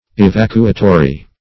Evacuatory \E*vac"u*a*to*ry\, n.